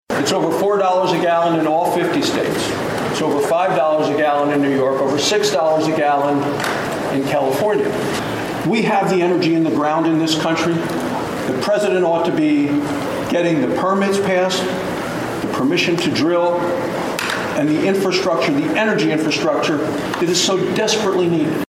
Wyoming Republican Senator John Barrasso says those are hot election-year topics on Capitol Hill.